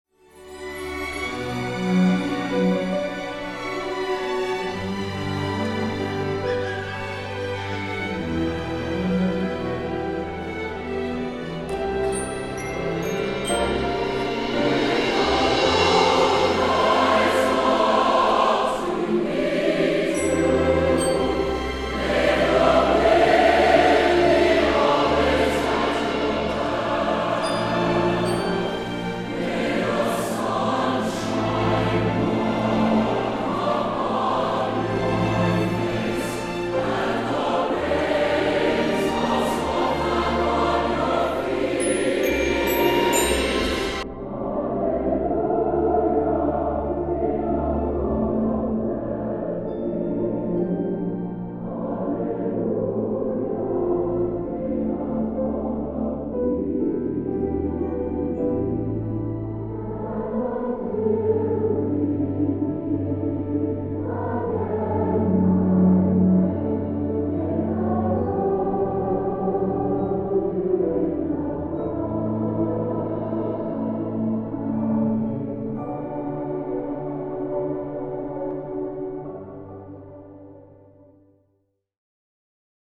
Audio sample of Hearing Loss
Frequencies beginning at 500 Hz begin to be filtered out. Frequencies at 1000 Hz are attenuated by 12 dB and at 2000 Hz, the ear hears nothing.
The recording was taken from a concert by the Lexington Singers with the Lexington Singers Children's Choir. The first 45 seconds are recorded as if your hearing is normal. You will easily be able to hear the diction of the choir and the cymbals and glockenspiel.